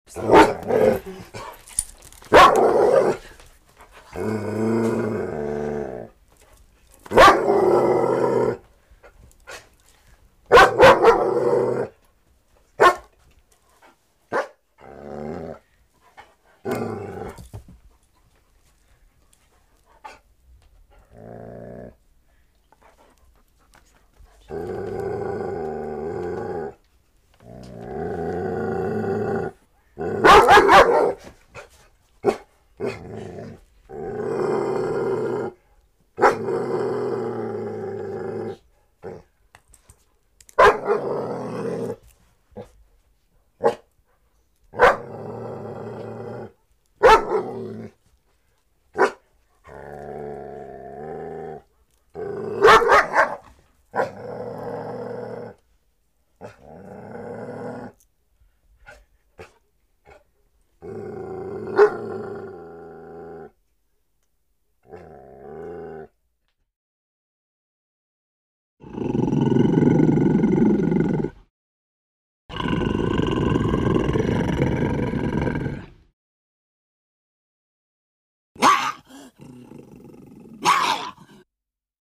Звуки монстров хижной живности и других страшных тварей существующих и нет...